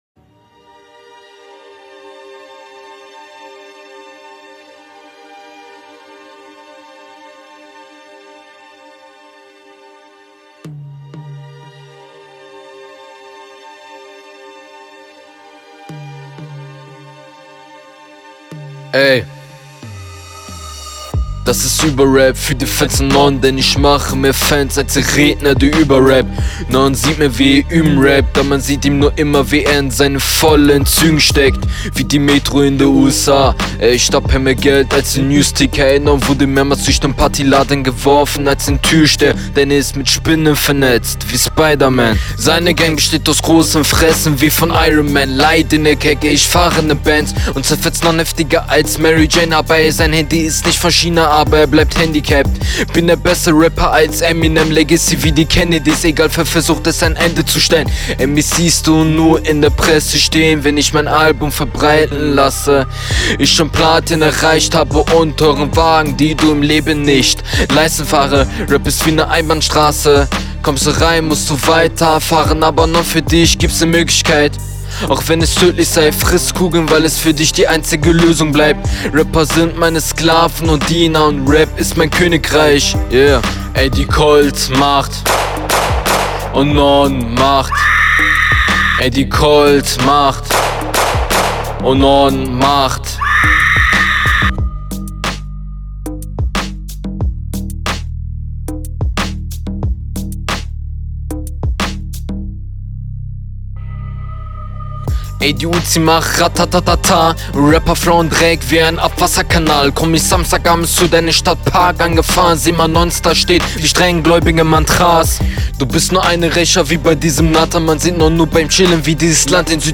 Okay man muss anmerken, du hast versucht stärker zu delivern.. aber wie in …